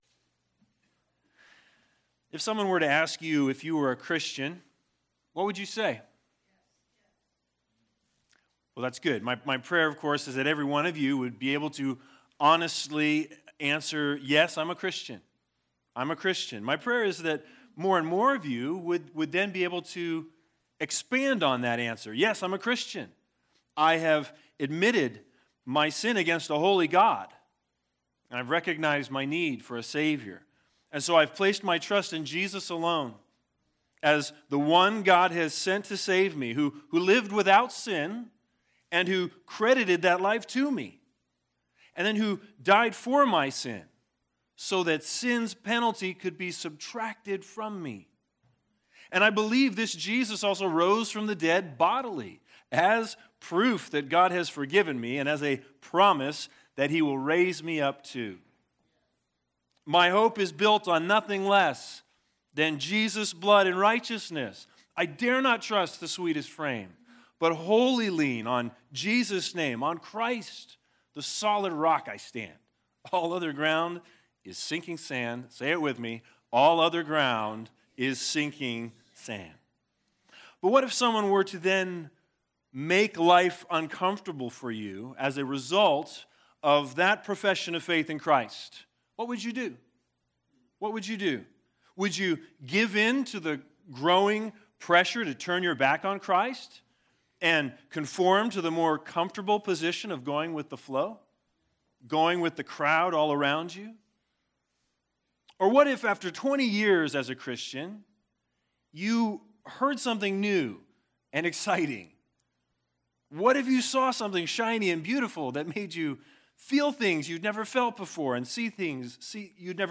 Matthew 24:9-13 Service Type: Sunday Sermons The Big Idea